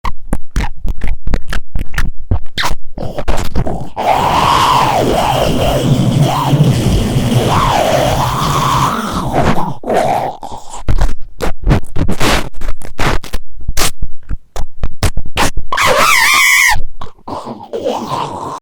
Riconosciamo la voce di Momo (18) molto spaventosa (256 kbps)